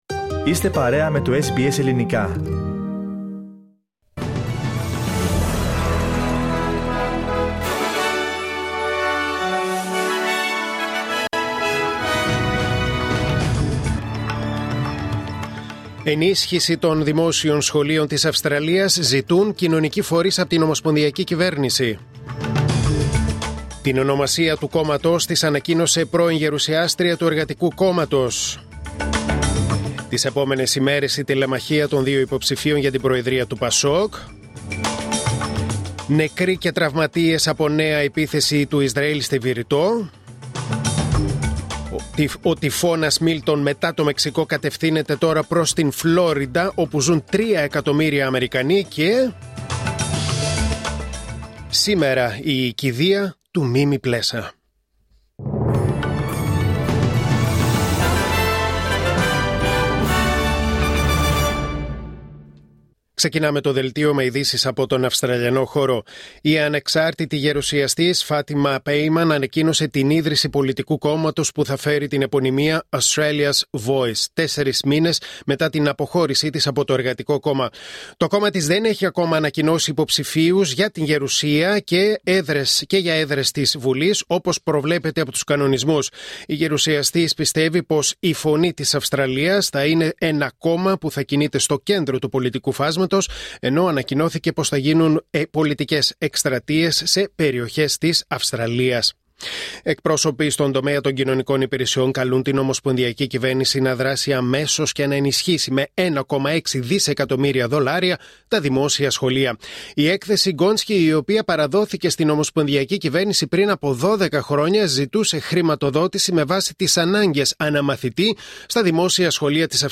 Δελτίο Ειδήσεων Τετάρτη 09 Οκτωβρίου 2024